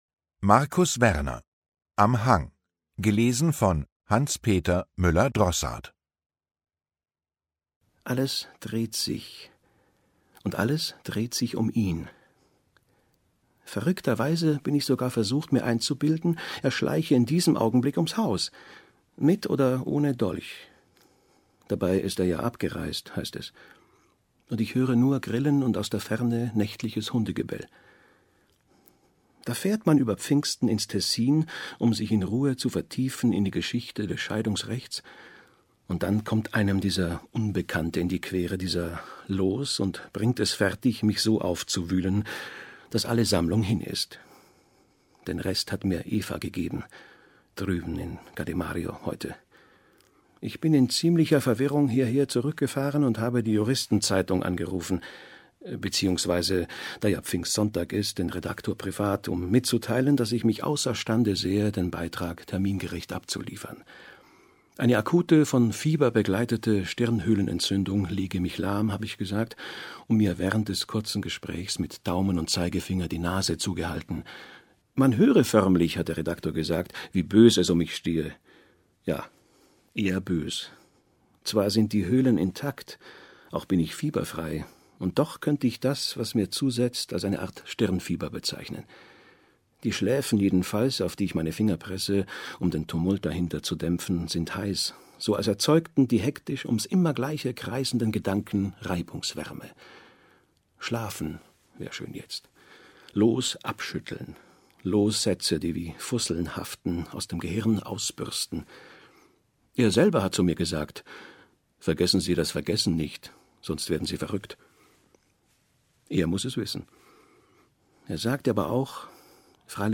Lesung mit Hanspeter Müller-Drossaart (1 mp3-CD)
Hanspeter Müller-Drossaart (Sprecher)